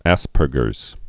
(ăspər-gər) or As·per·ger's syndrome (-gərz)